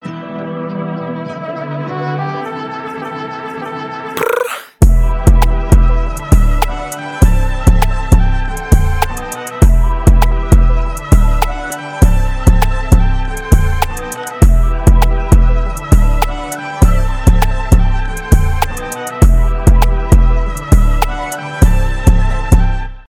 2025 » Новинки » Без Слов » Рэп » Тик Ток Скачать припев